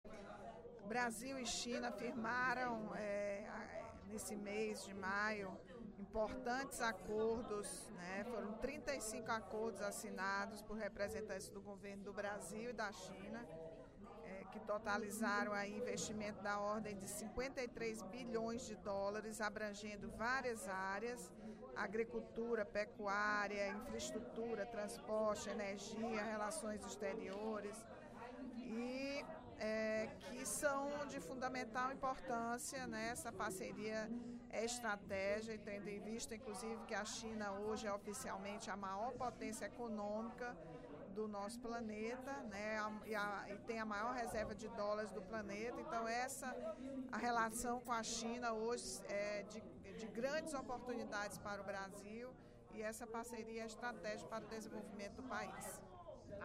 A deputada Rachel Marques (PT) destacou as parcerias fechadas entre Brasil e China, durante o primeiro expediente da sessão plenária desta terça-feira (02/06). A comitiva chinesa visitou o País em maio, quando foram firmados 35 acordos em oito áreas da economia, com um valor total de US$ 53 bilhões.